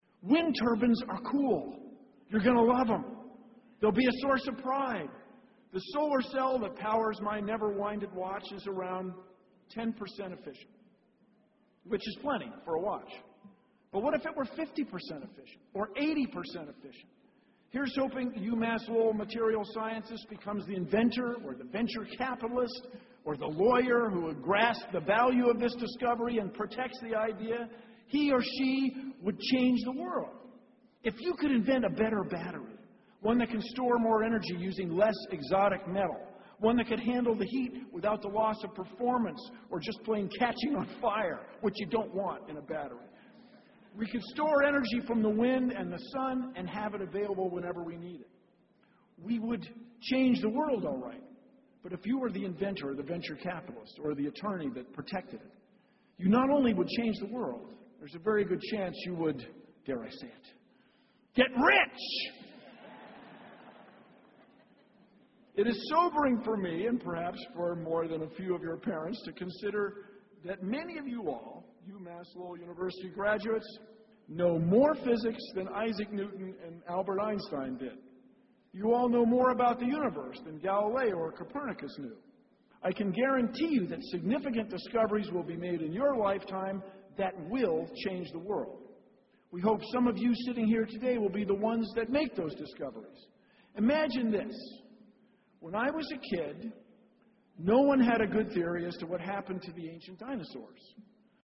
公众人物毕业演讲 第165期:比尔·奈马萨诸塞大学2014(12) 听力文件下载—在线英语听力室